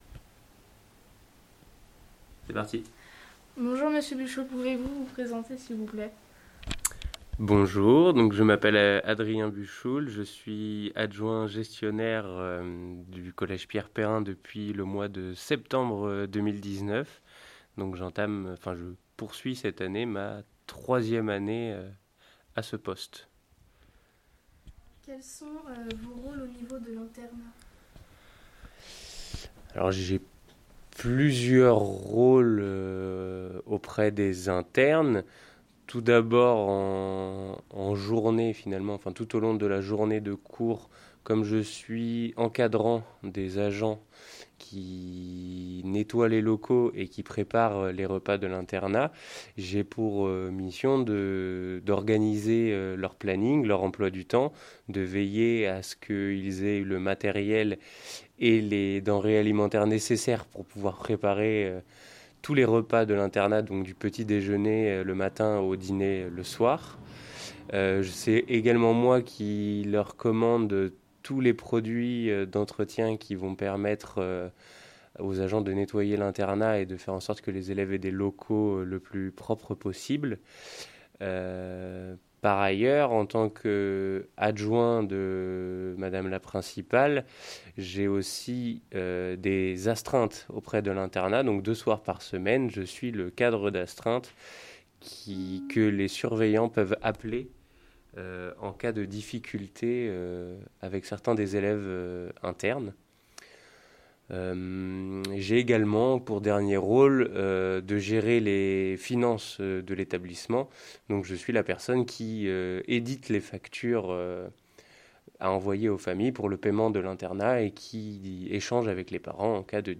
Interview gestionnaire